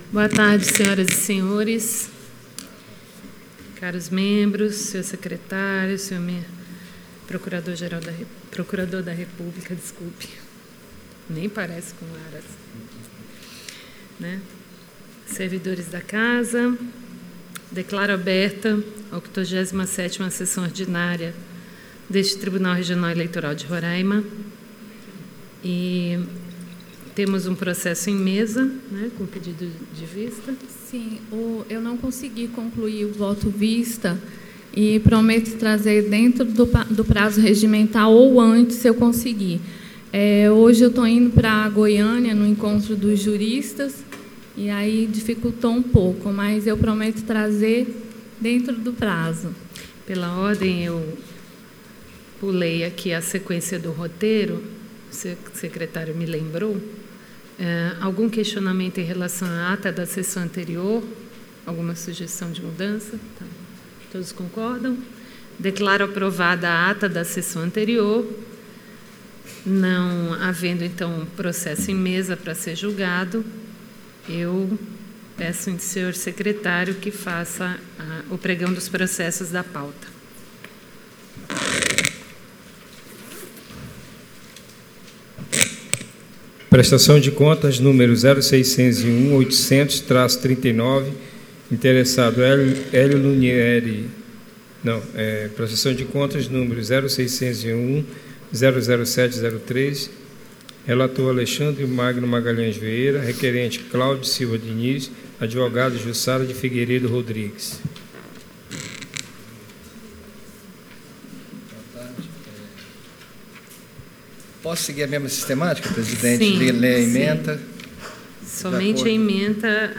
ÁUDIO da 87ª Sessão Ordinária de 27 de novembro de 2019.